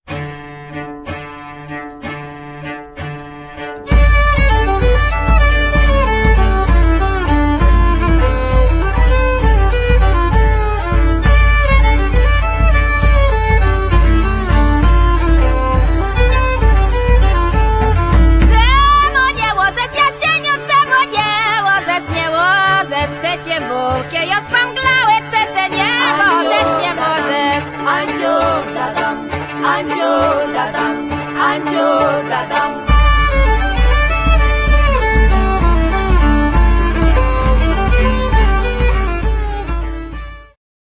wiolonczela cello
cymbały dulcimer
nyckelharpa